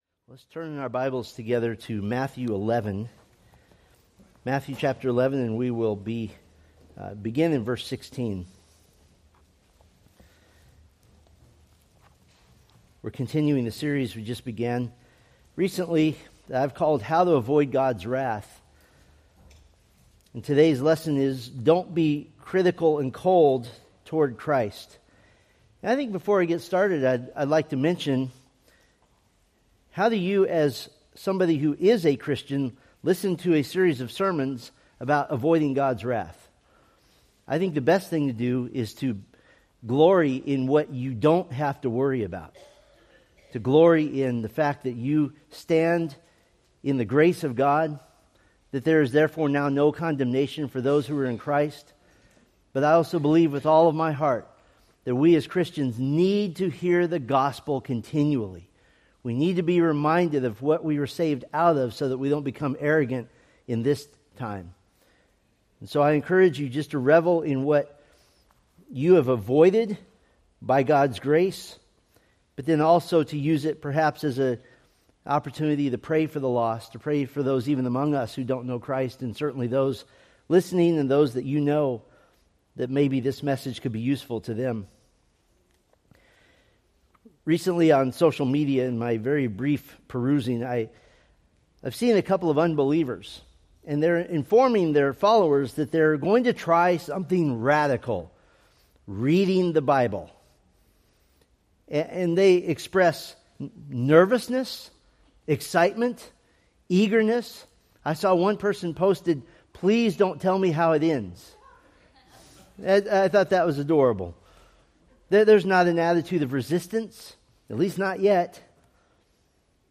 From the How to Avoid God's Wrath sermon series.
Sermon Details